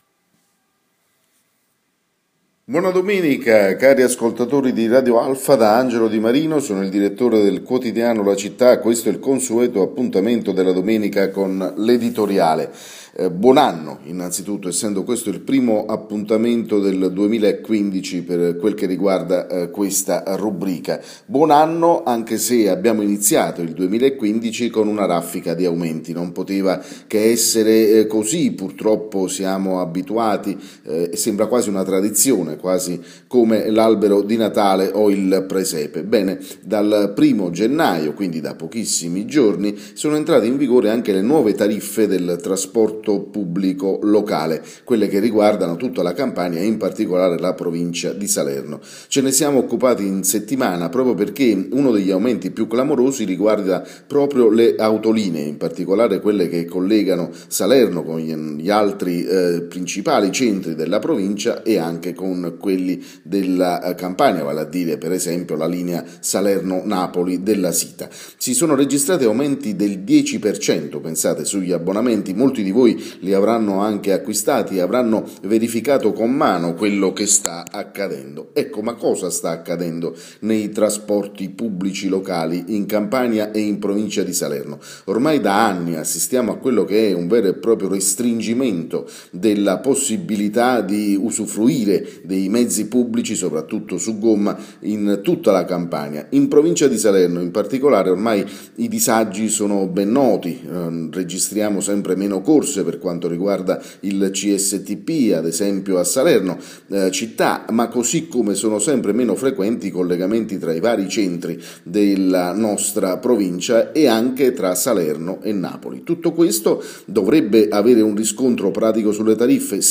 L’editoriale andato in onda alle ore 9 su Radio Alfa.